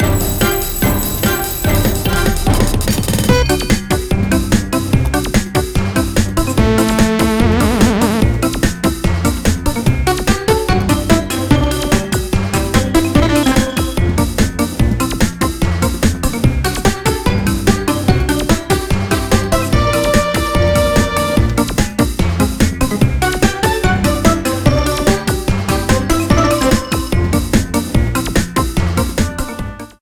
Frontrunning music